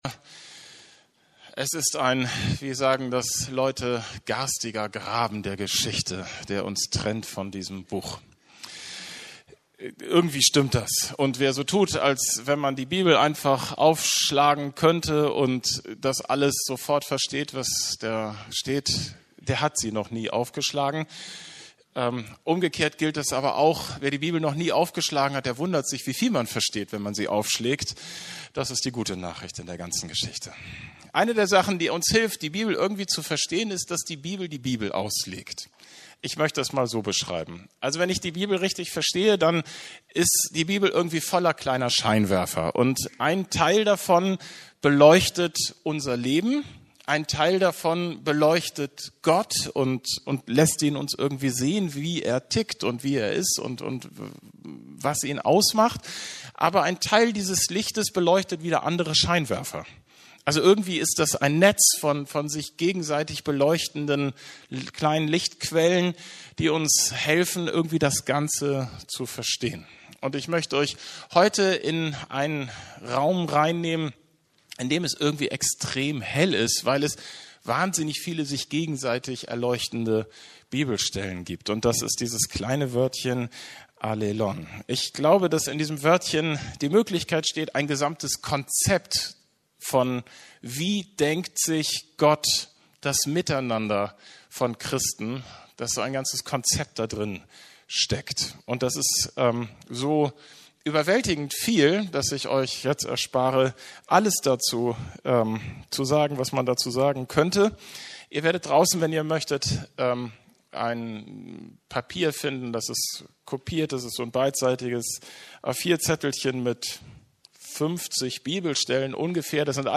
Allelon wie ein kleines Wort unser Herz herausfordert ~ Predigten der LUKAS GEMEINDE Podcast